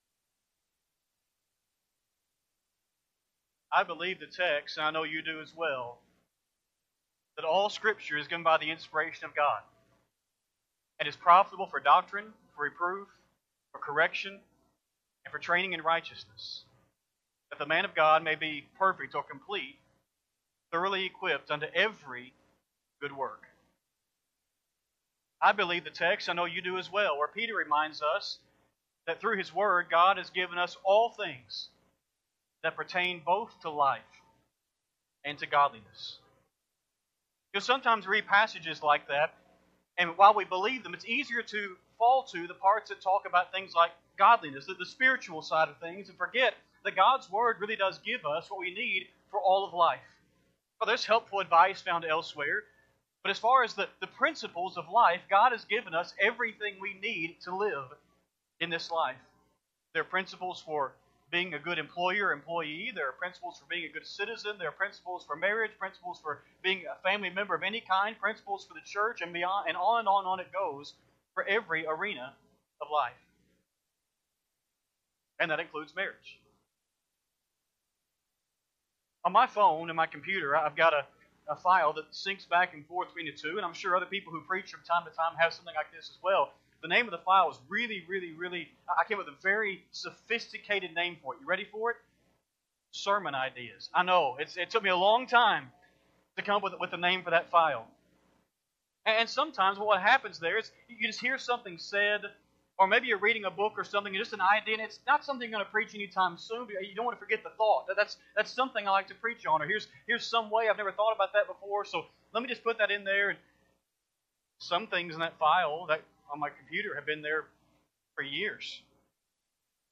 Sunday AM Sermon
8-24-25-Sunday-AM-Sermon.mp3